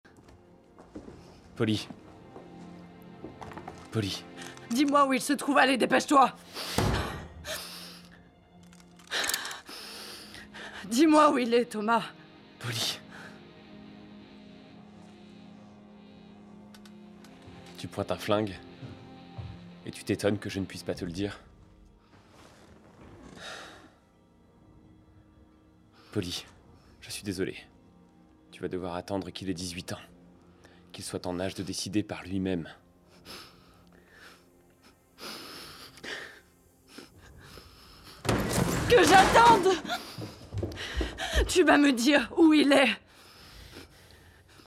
IMDA - Doublage Polly - Peaky blinders